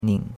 nin3.mp3